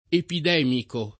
epidemico [ epid $ miko ]